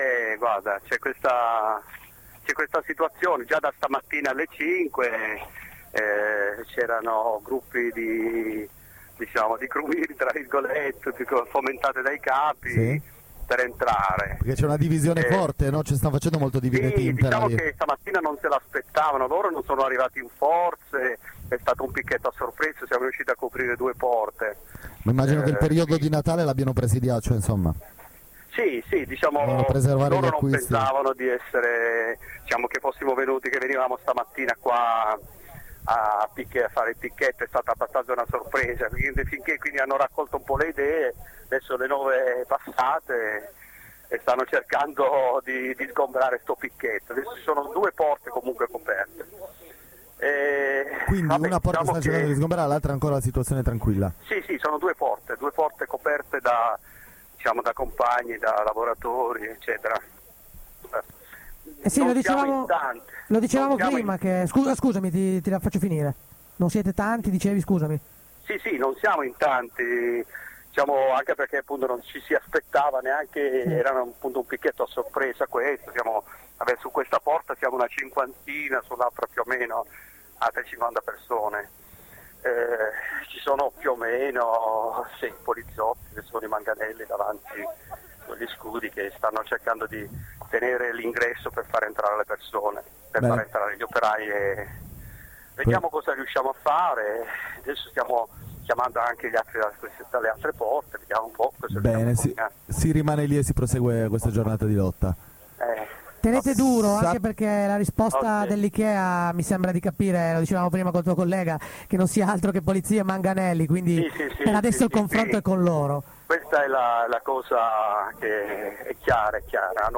Due dirette con i compagni sul posto: